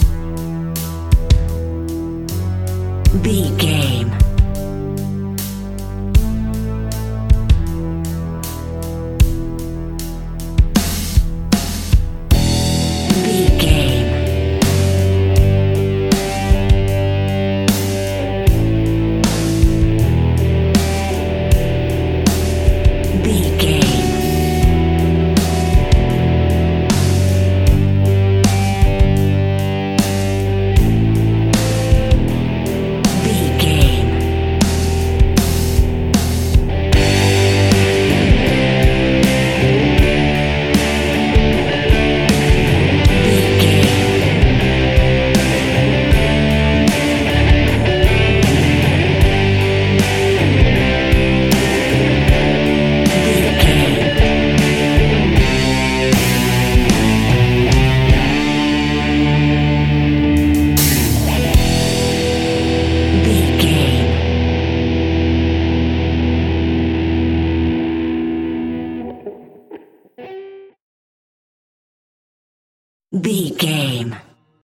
Epic / Action
Aeolian/Minor
hard rock
blues rock
distortion
rock guitars
Rock Bass
Rock Drums
heavy drums
distorted guitars
hammond organ